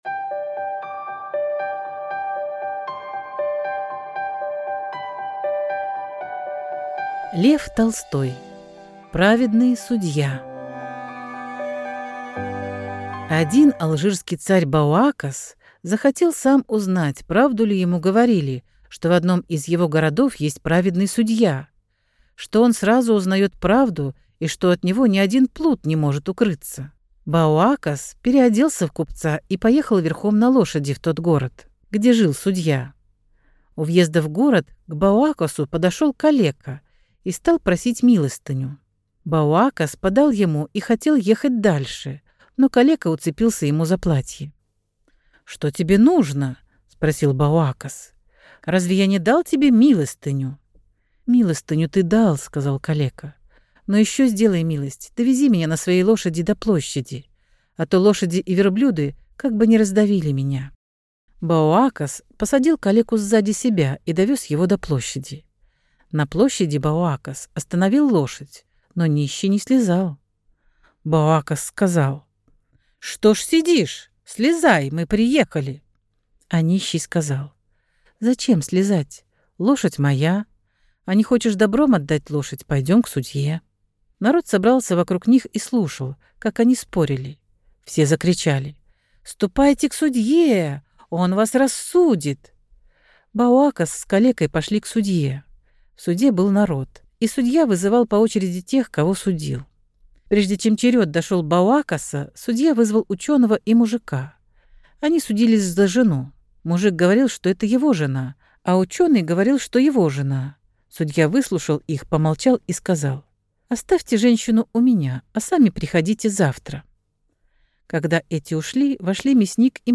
Праведный судья – Толстой Л.Н. (аудиоверсия)
Аудиокнига в разделах